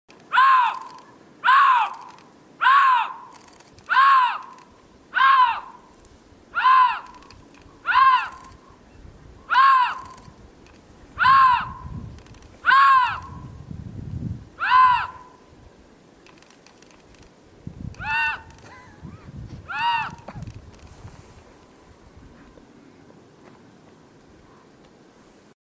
This is how the Siberian lynx sounds in breeding season.